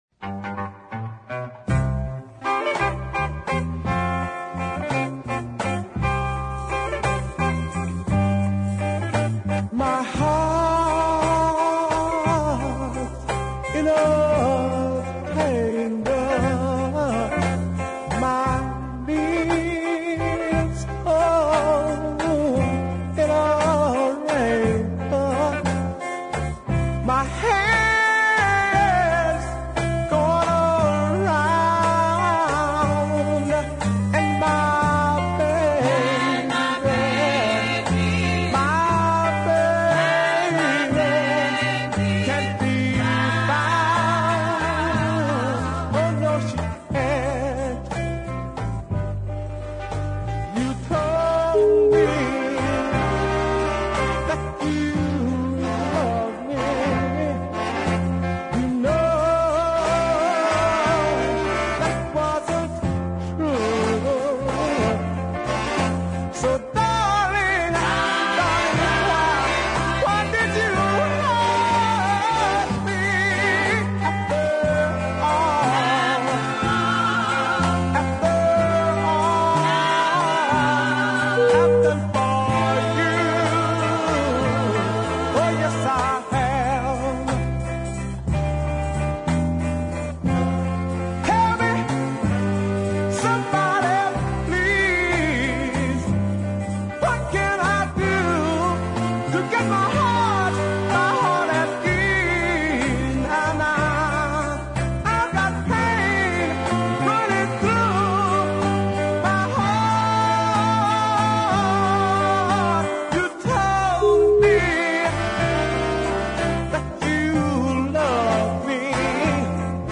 with most of his tracks being strong uptempo southern soul.
Better by far is the deep ballad
aided by some female background singers